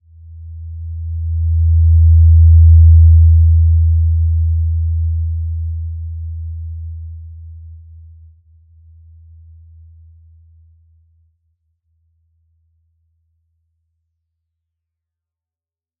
Simple-Glow-E2-mf.wav